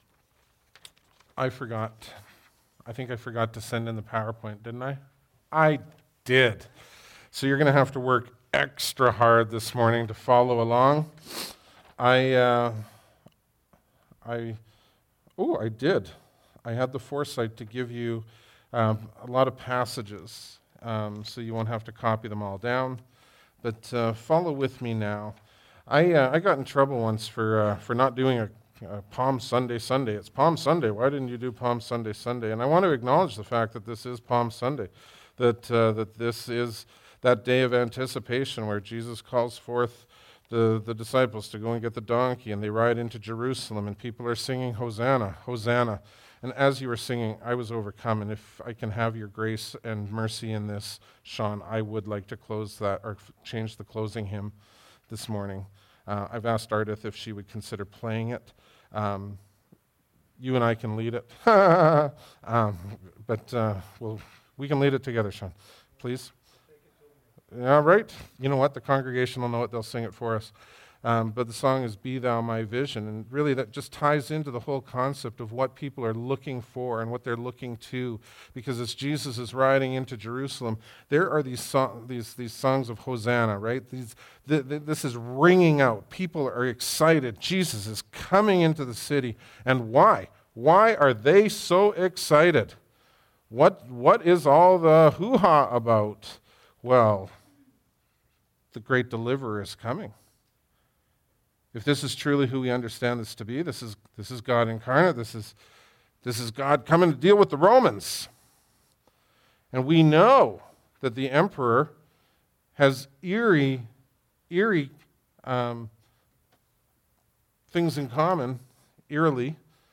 Bible Text: Exodus 11:1-12-28 | Preacher: